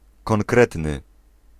Ääntäminen
IPA : /ɪnˈtɛns/